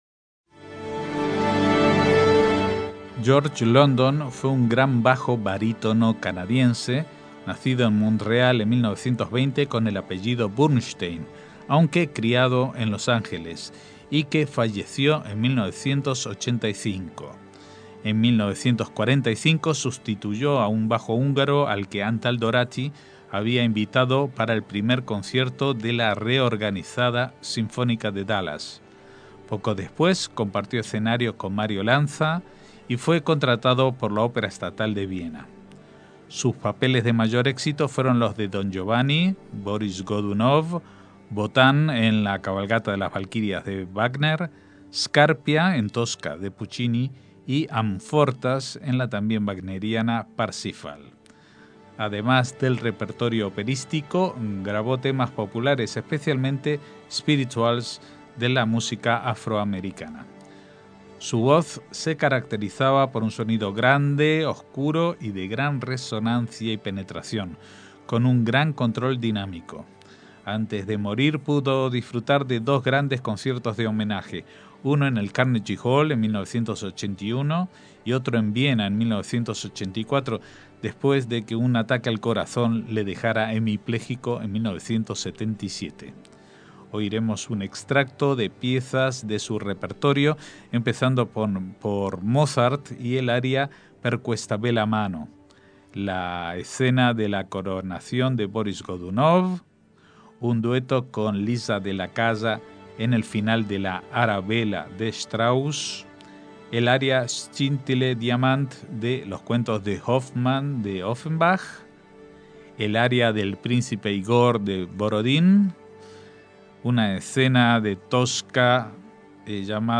bajo barítono